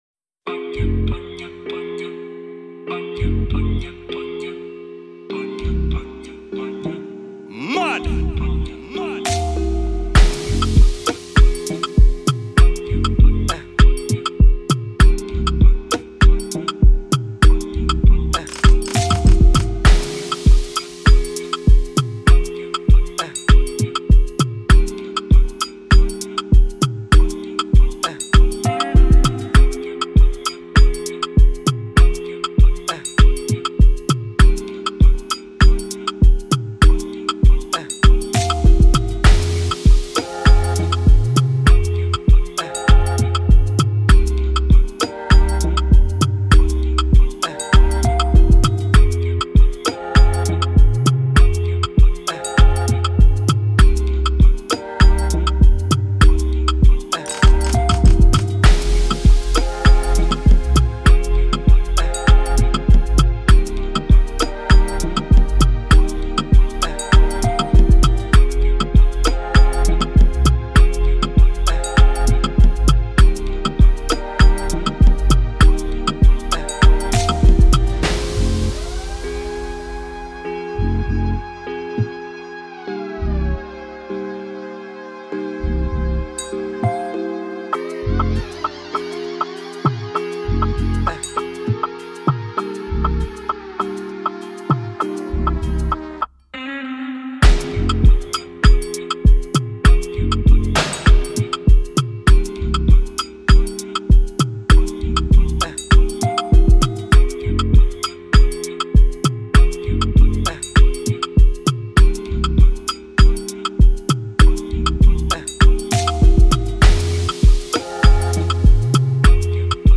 2020 in Dancehall/Afrobeats Instrumentals